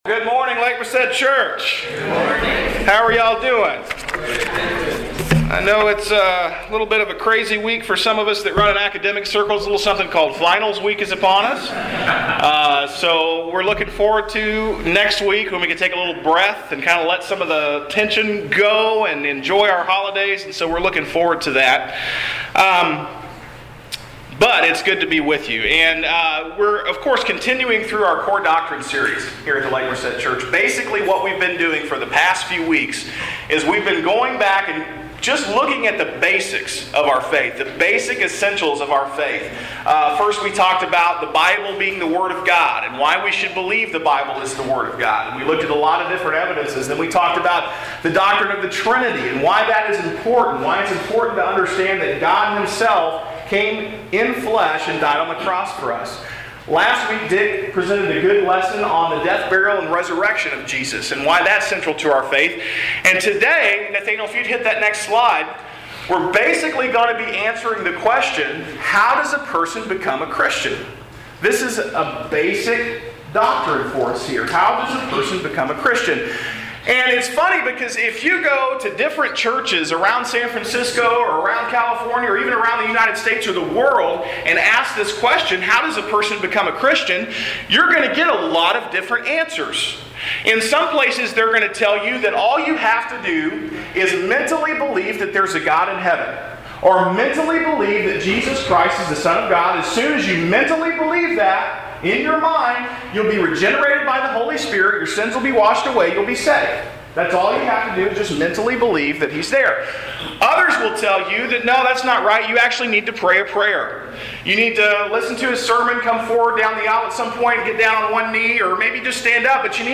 We’re working our way through our One Purpose sermons series at Lake Merced Church.
Yesterday the sermon was about declaring the Good News of Jesus in everyday life.